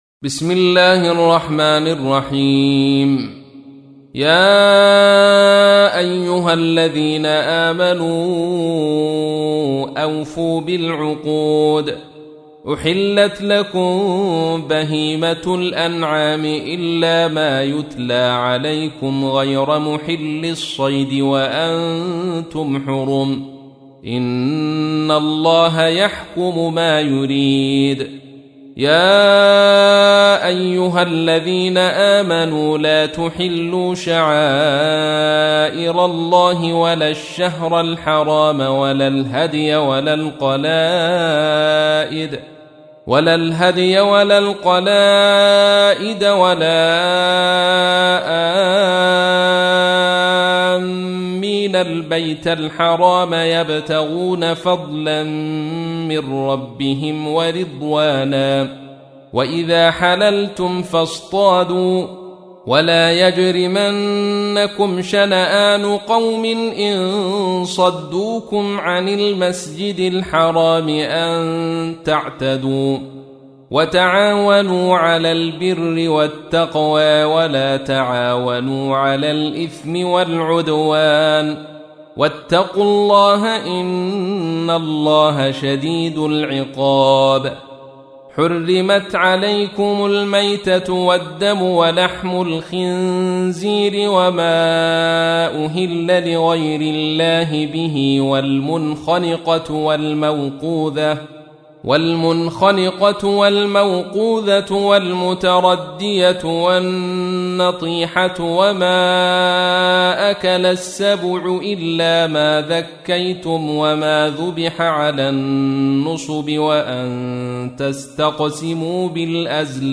تحميل : 5. سورة المائدة / القارئ عبد الرشيد صوفي / القرآن الكريم / موقع يا حسين